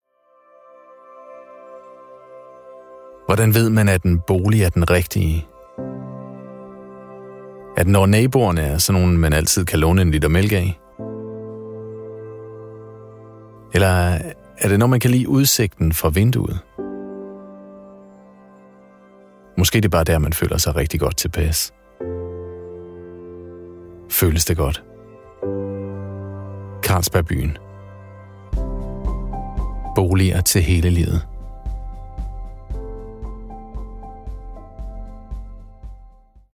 Male
Approachable, Character, Conversational, Corporate, Energetic, Engaging, Friendly, Gravitas, Natural, Reassuring, Sarcastic, Soft, Upbeat, Versatile, Warm
Microphone: Neumann u87